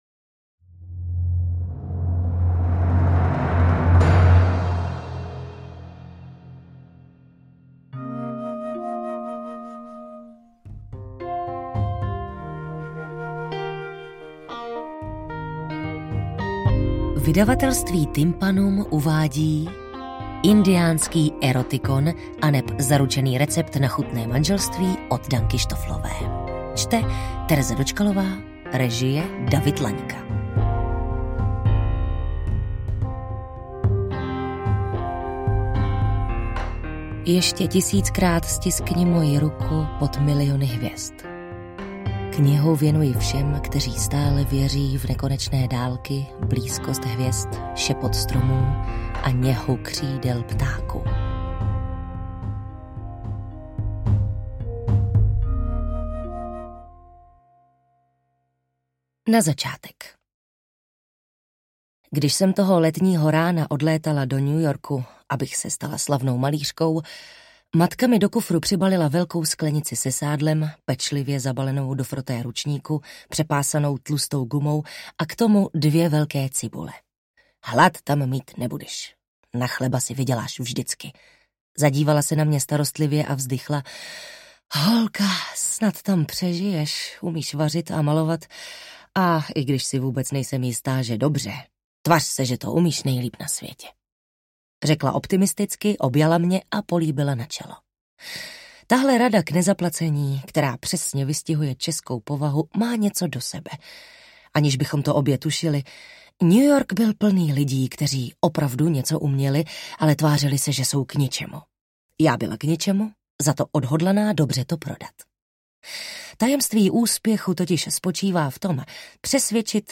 AudioKniha ke stažení, 42 x mp3, délka 10 hod. 46 min., velikost 596,9 MB, česky